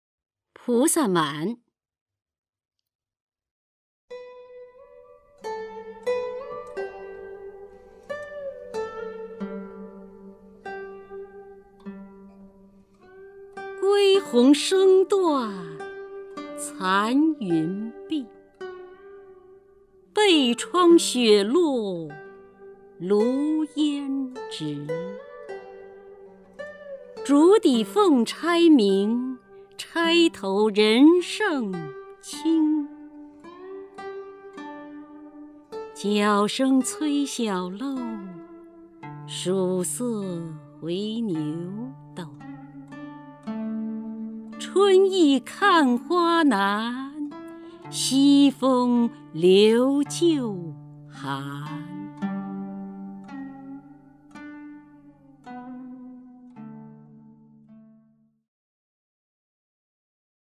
首页 视听 名家朗诵欣赏 姚锡娟
姚锡娟朗诵：《菩萨蛮·归鸿声断残云碧》(（南宋）李清照)
PuSaManGuiHongShengDuanCanYunBi_LiQingZhao(YaoXiJuan).mp3